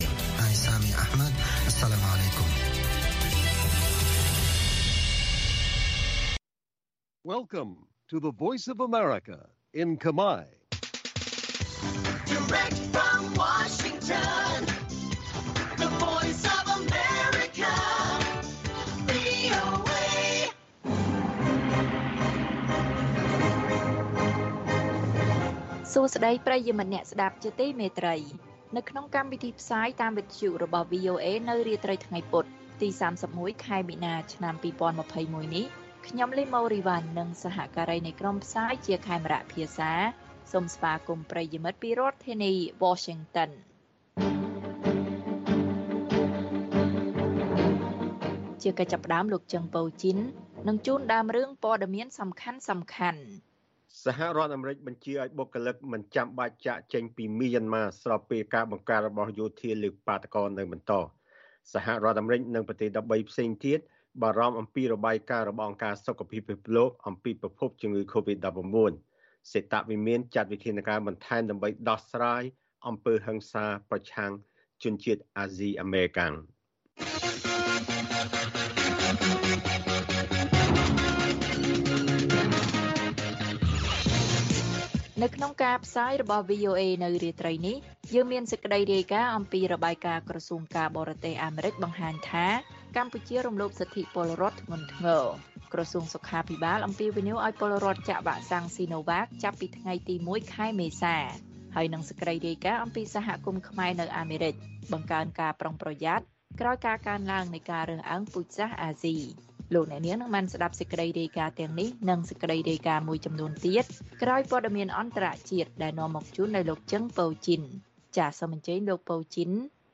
ព័ត៌មានពេលរាត្រី៖ ៣១ មីនា ២០២១